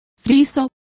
Complete with pronunciation of the translation of friezes.